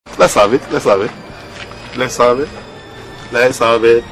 lets have it lets have it Meme Sound Effect